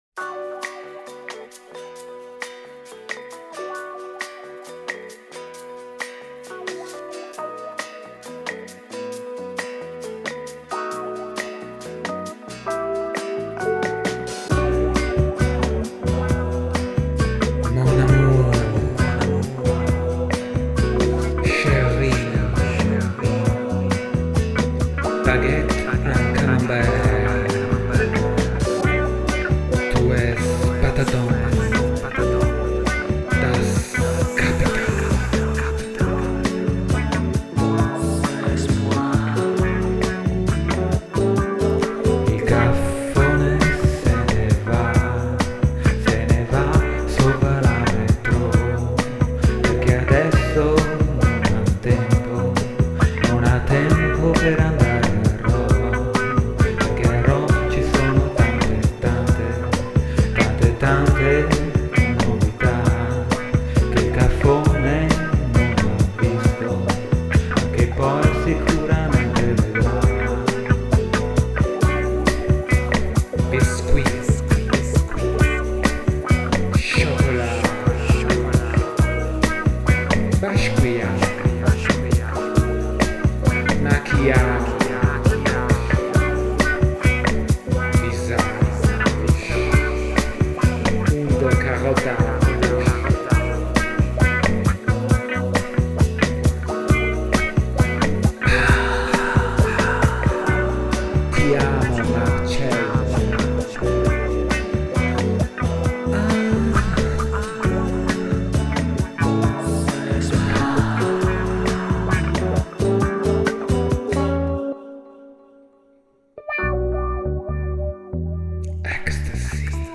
electric guitars,programming
vocals